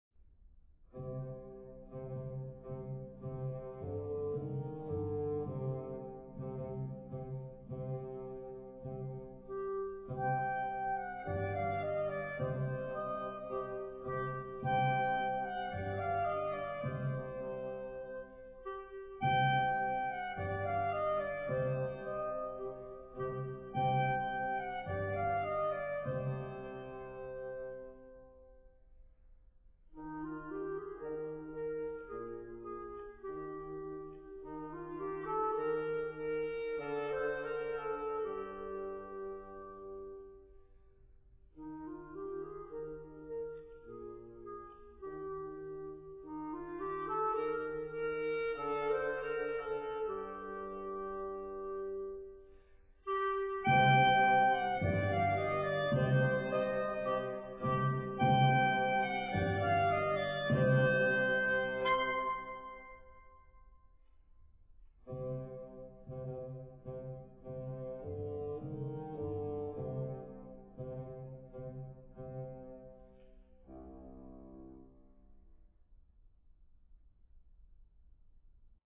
09_marcia_funebre.mp3